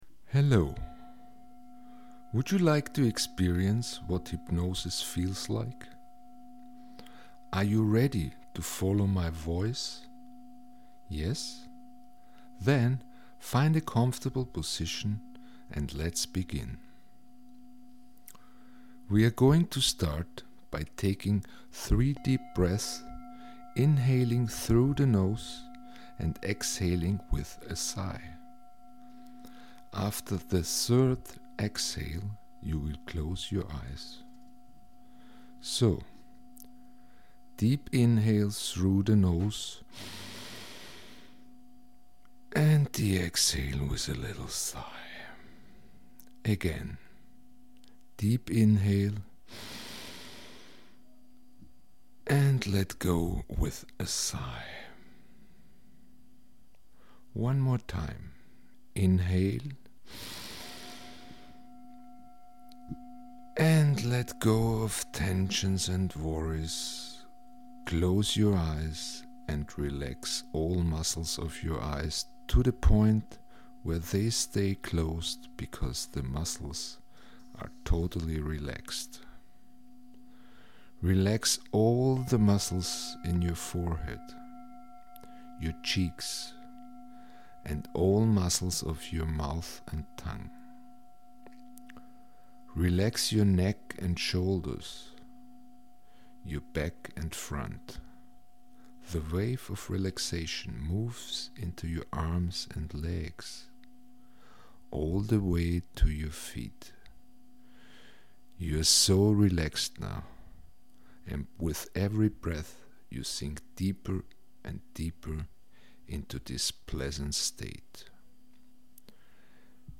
In case you may sit back and relax I wish to offer you a short demonstration to hear and feel a hypnotic state.